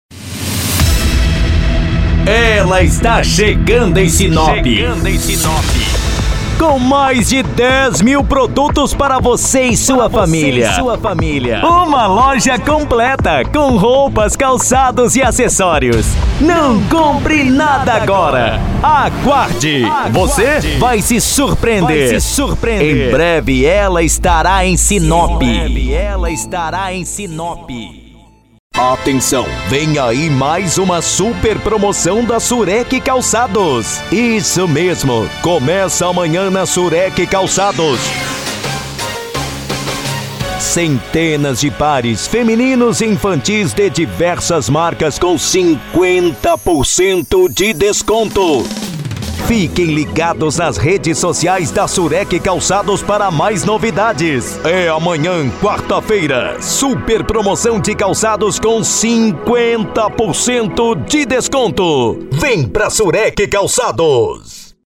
Demonstrativo Voz Impacto :
Padrão
Impacto
Animada